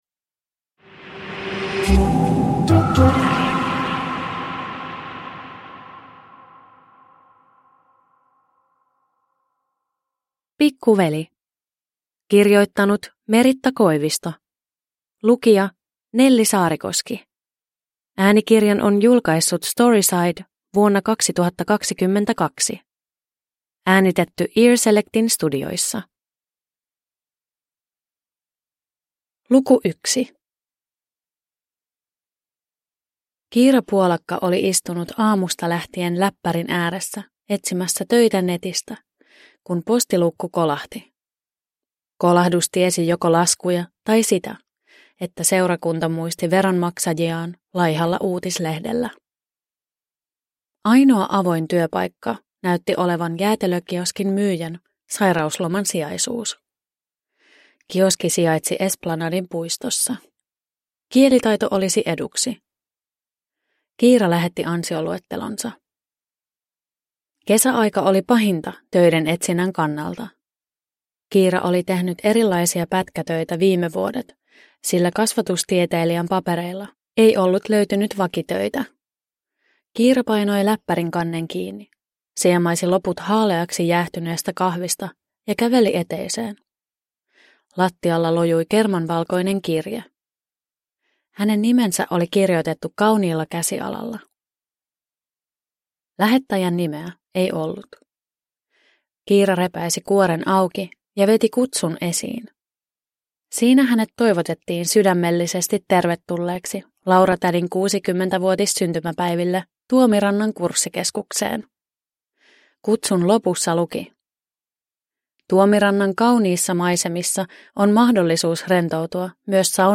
Pikkuveli – Ljudbok – Laddas ner